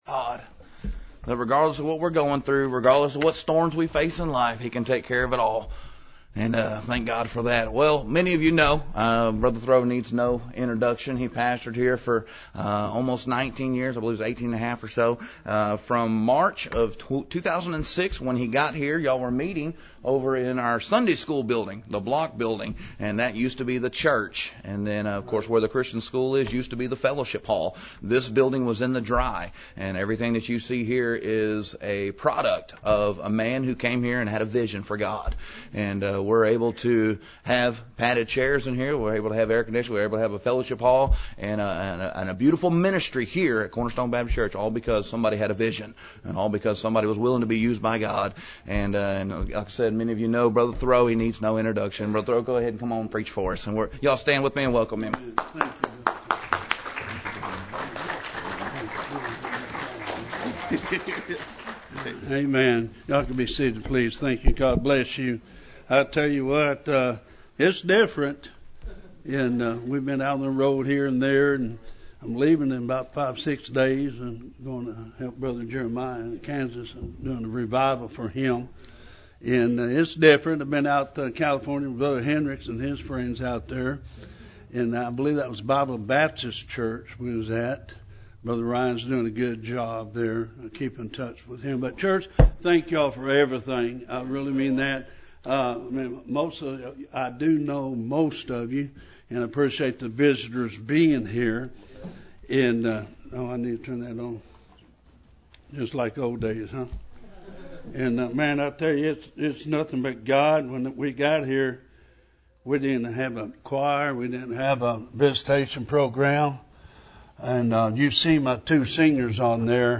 preaches for us on our 35th church anniversary of Cornerstone Baptist Church.